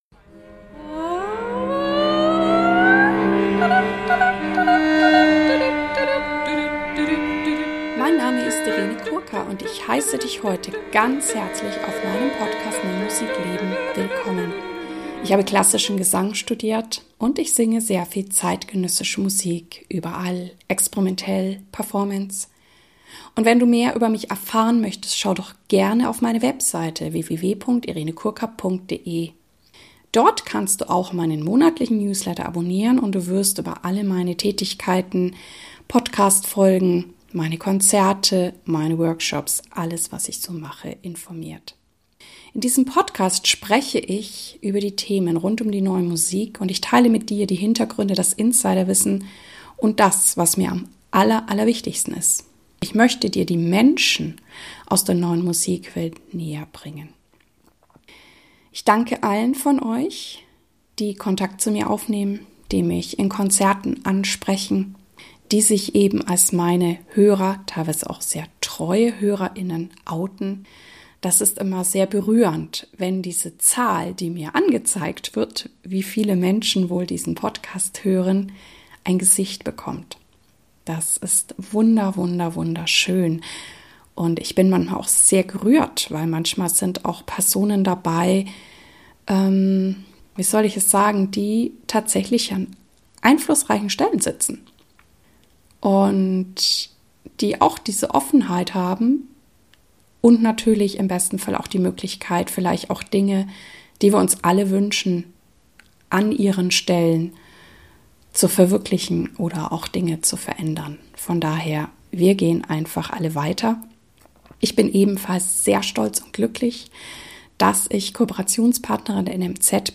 268 - Interview